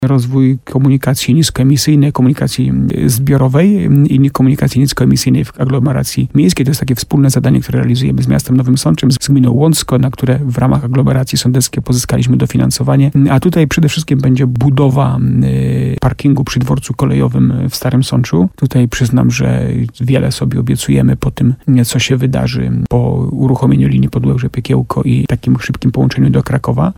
Będzie też druga inwestycja, tym razem z unijnych dotacji. Jak mówił w programie Słowo za Słowo w radiu RDN Nowy Sącz burmistrz Starego Sącza Jacek Lelek, miasto wiąże z nią duże nadzieje.